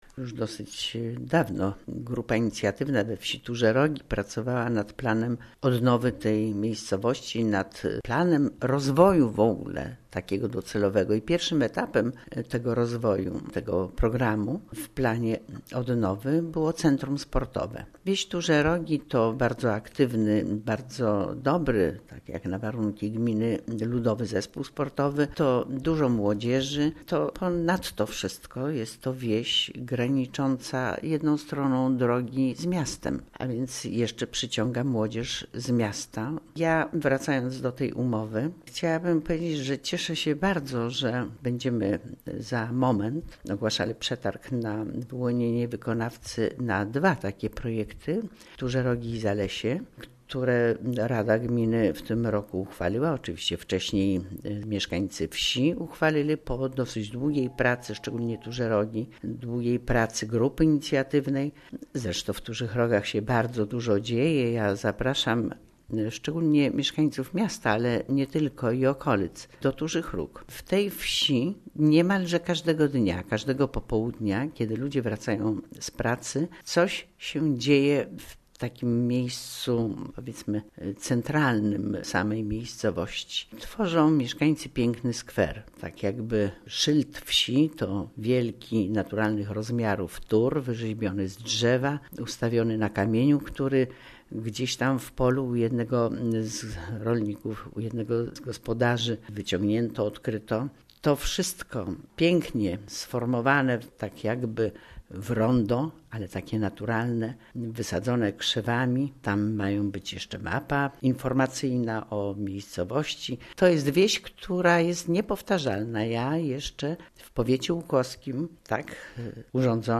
Wójt Gminy Łuków